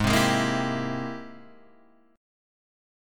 G#+M9 chord